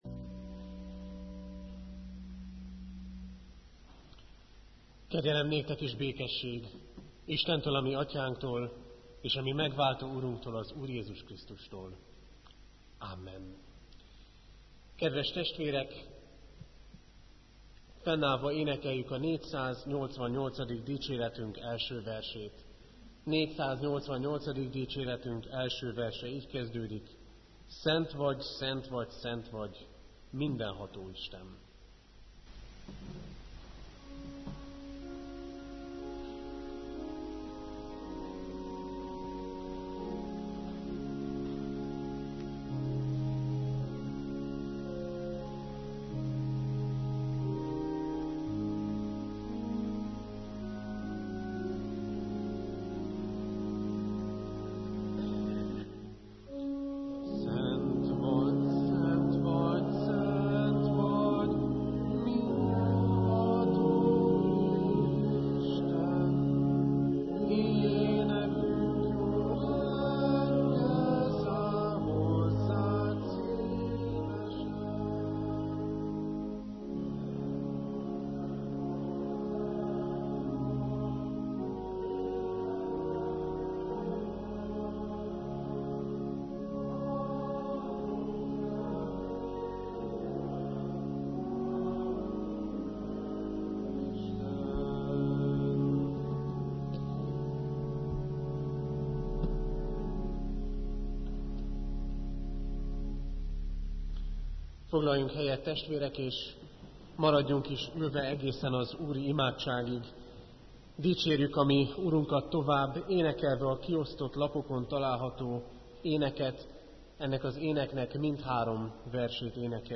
Igehirdetések Szent élet